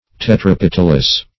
Search Result for " tetrapetalous" : The Collaborative International Dictionary of English v.0.48: Tetrapetalous \Tet`ra*pet"al*ous\, a. [Tetra- + petal.]